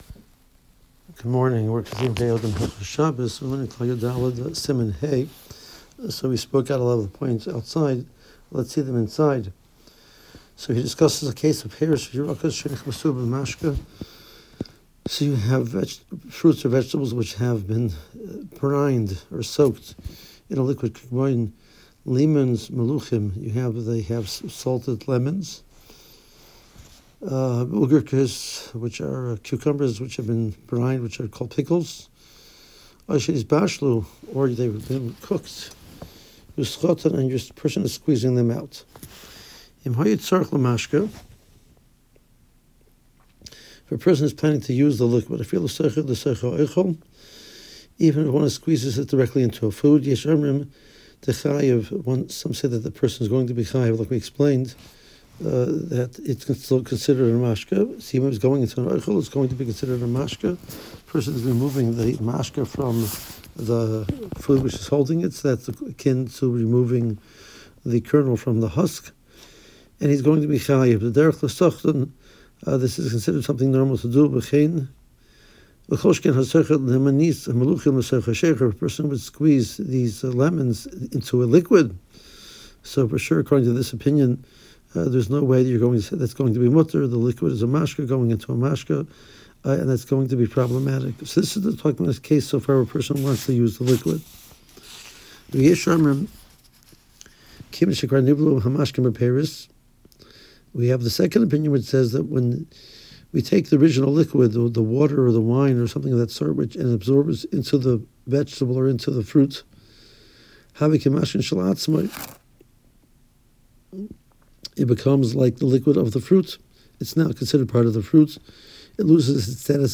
• 5 Min. Audio Shiur Including Contemporary Poskim